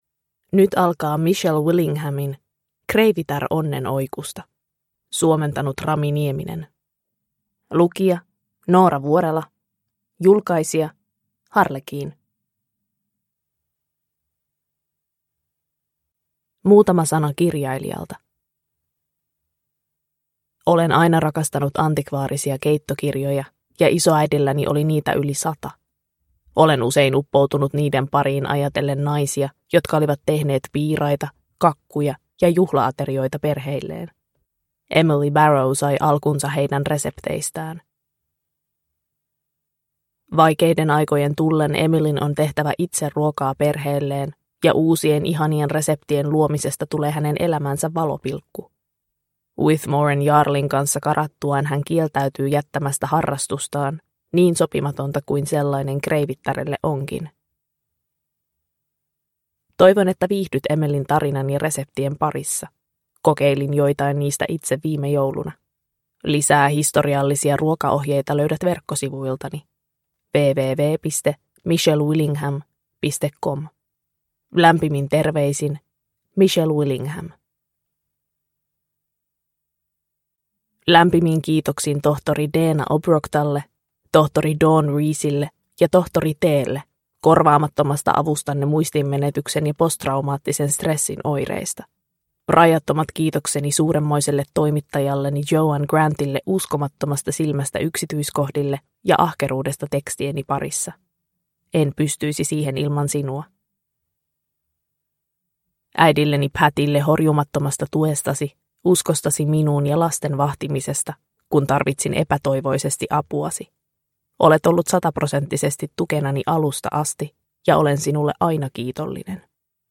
Kreivitär onnen oikusta (ljudbok) av Michelle Willingham | Bokon